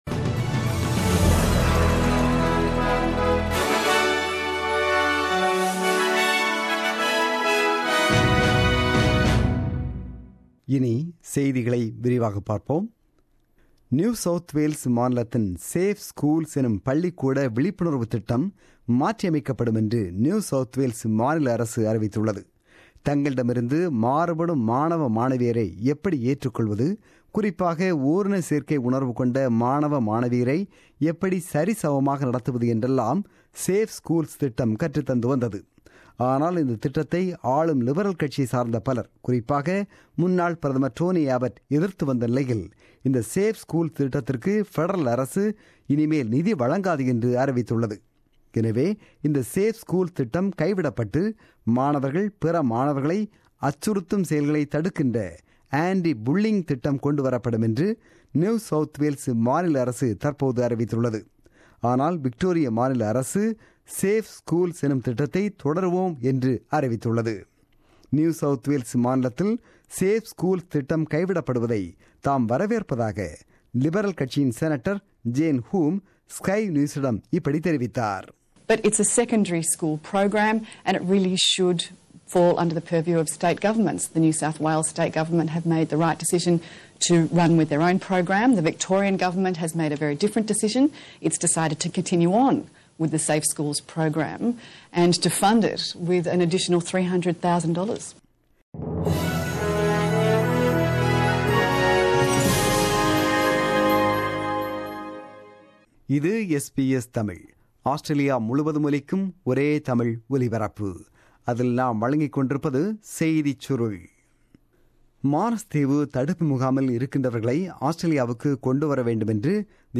The news bulletin broadcasted on 16 April 2017 at 8pm.